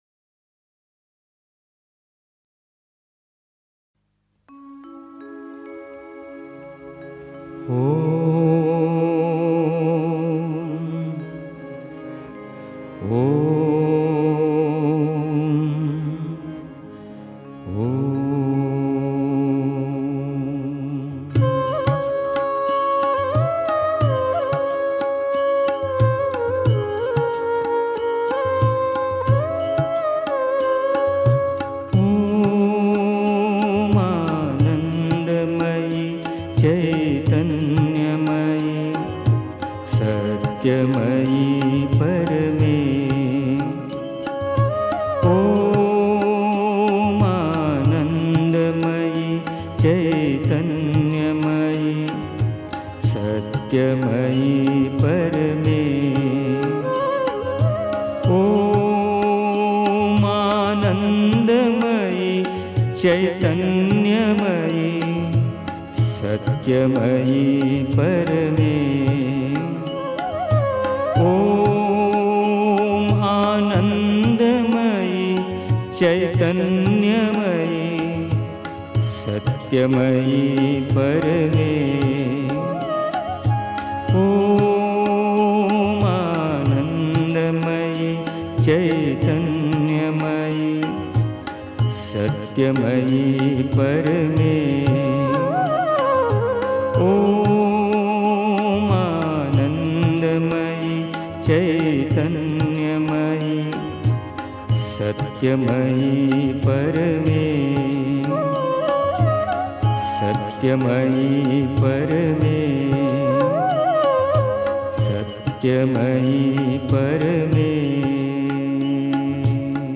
1. Einstimmung mit Musik. 2. Jemand, der sehr wenig weiß (Die Mutter, The Sunlit Path) 3. Zwölf Minuten Stille.